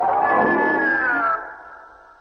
Play Super Metroid Mother Brain Roar - SoundBoardGuy
Play, download and share Super Metroid Mother Brain roar original sound button!!!!
mothra-roar.mp3